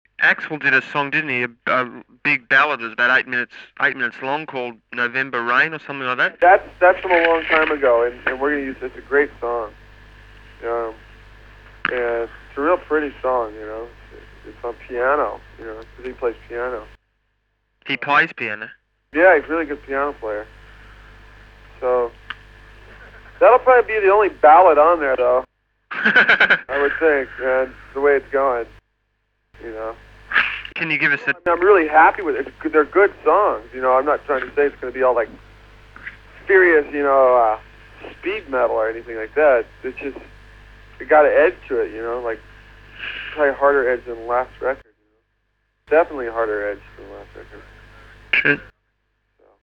SHORTLY after Guns N’Roses returned from their history-making Appetite For Destruction world tour in 1988, I interviewed Duff McKagan by phone from Los Angeles.